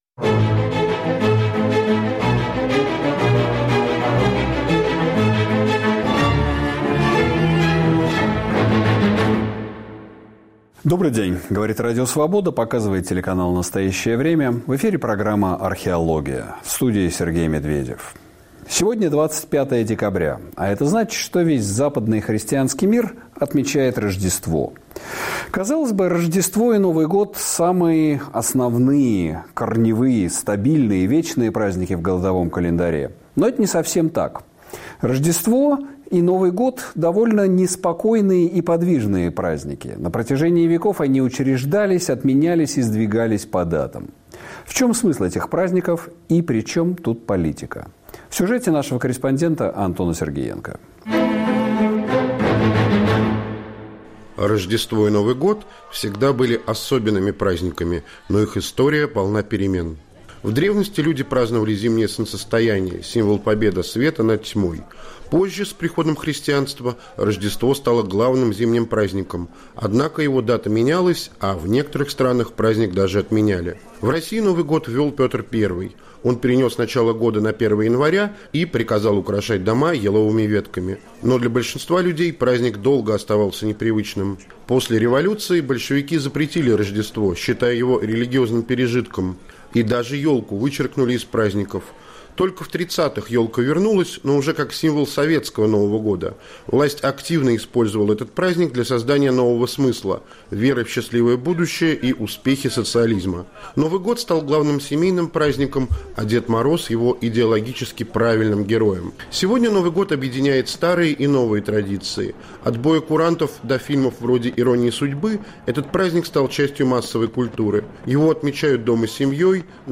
В гостях у Сергея Медведева богослов Андрей Кураев